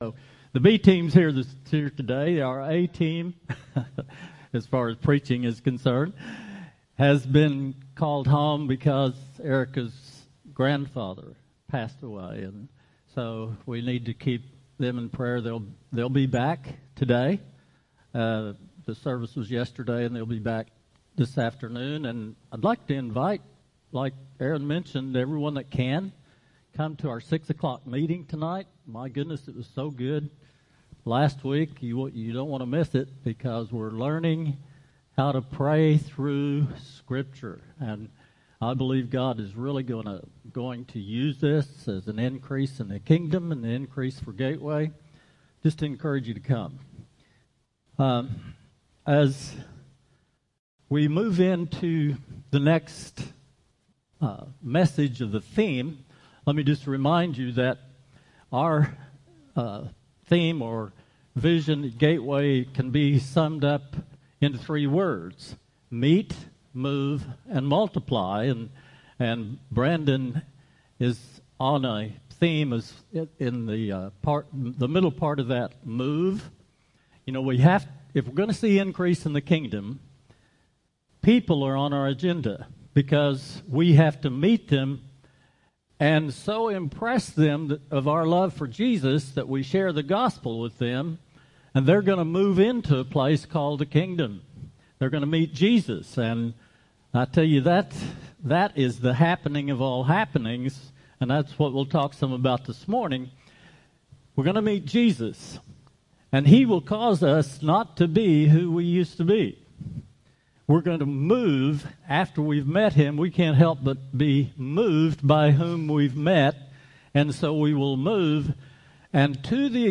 preaches a message